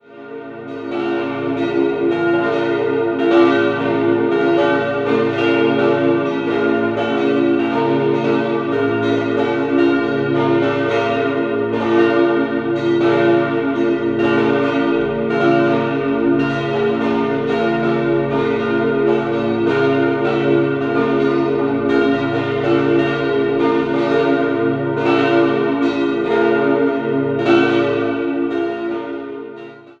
Hausen am Albis, Reformierte Kirche Hausen am Albis liegt im Süden des Kantons Zürich. Die reformierte Kirche wurde im Jahr 1751 errichtet und hatte bereits zwei Vorgängerbauten. 4-stimmiges Geläut: b°-d'-f'-b' Die Glocken wurden 1905 bei der Gießerei Rüetschi in Aarau gefertigt.